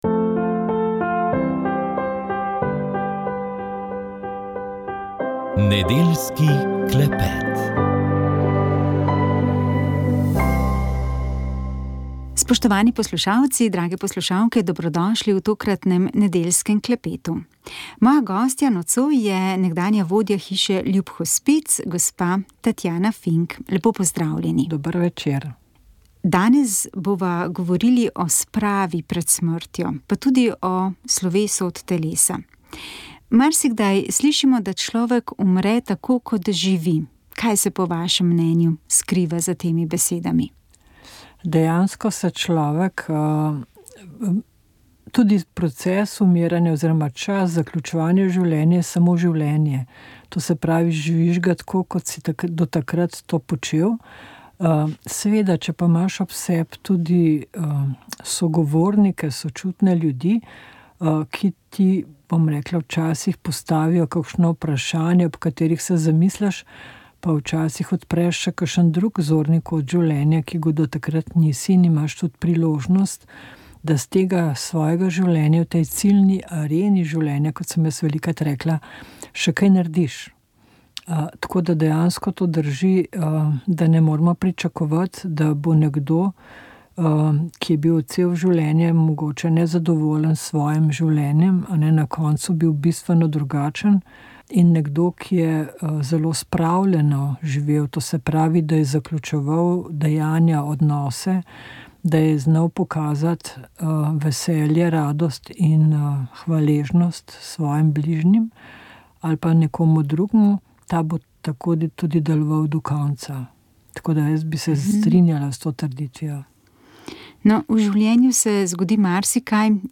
Oddaja je prinesla nekaj poletnih pesmi, glasbenih opomnikov, da se začenja lep čas leta. Otroci so slišali tudi, da morajo biti hvaležni za šolo, učitelje in možnost učenja.